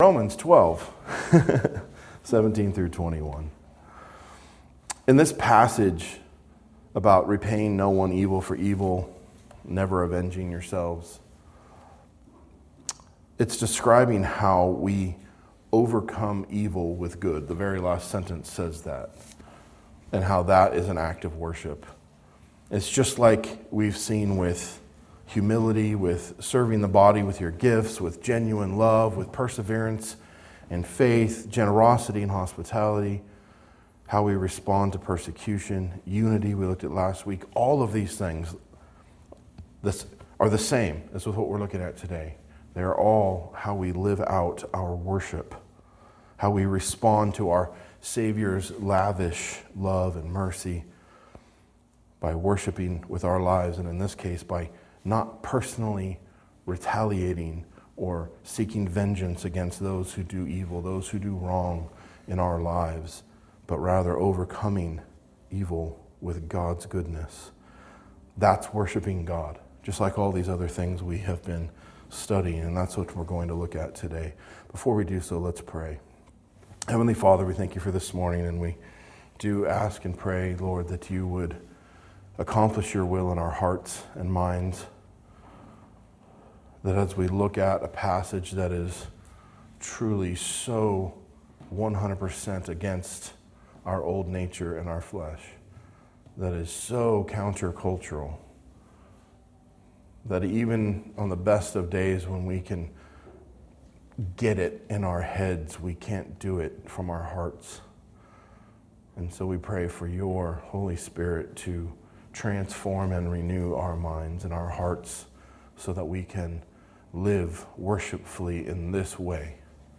A message from the series "Biblical Worship Series."